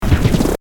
sons de trrring